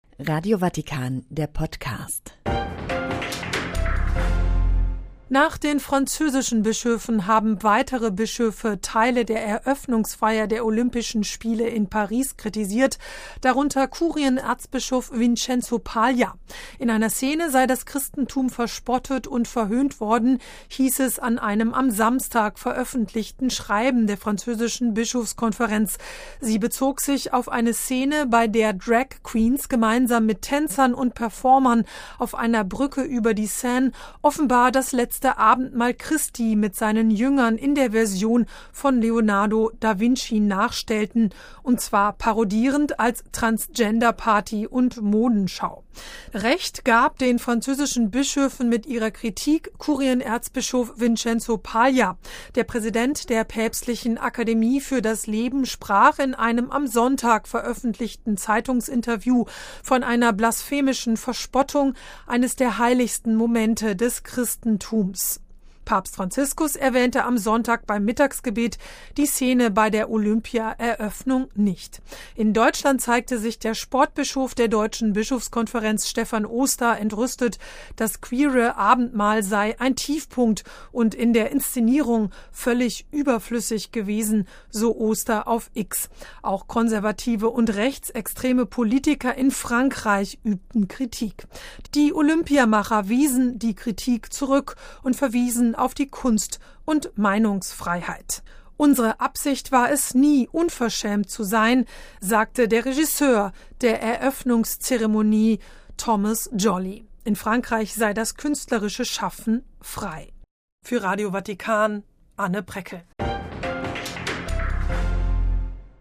From St. Peter's Square: The Angelus prayer with Pope Francis